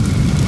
flamethrowerShoot.ogg